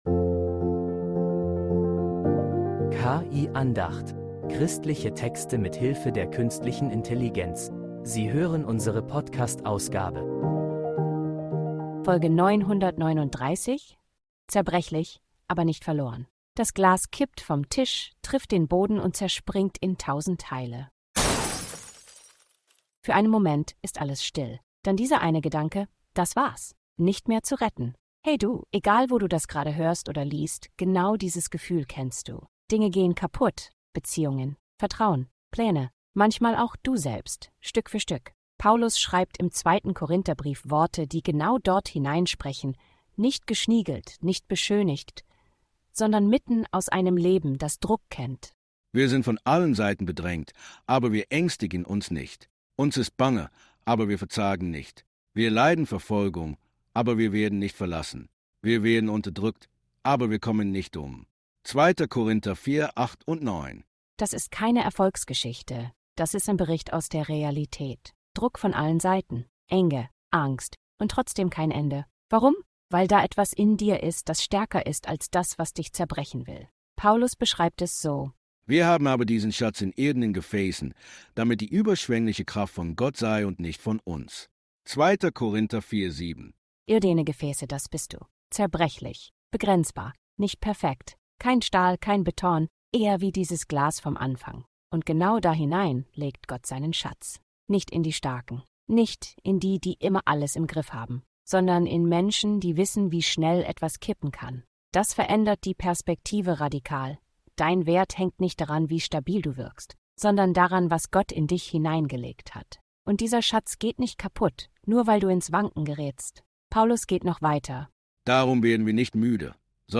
Eine Andacht über Risse, Druck und eine überraschende Stärke.